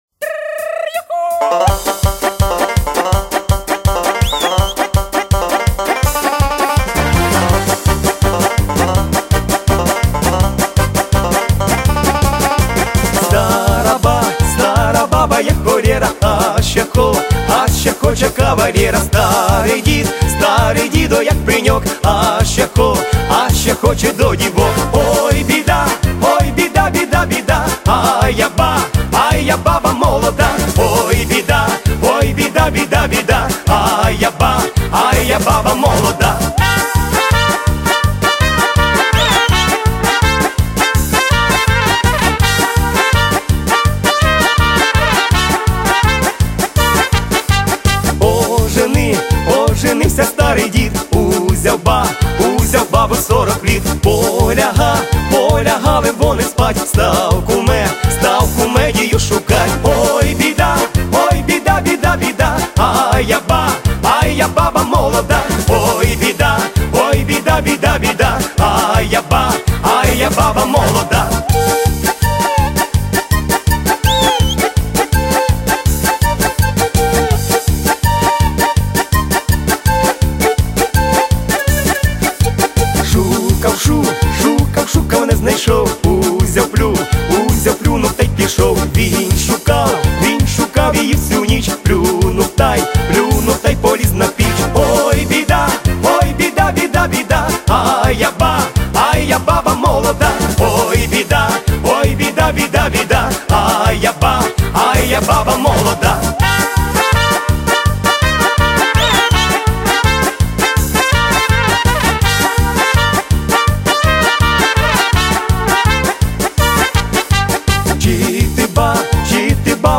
vesilni_pisni___oj_bida__a_ja_baba_moloda_z2_fm.mp3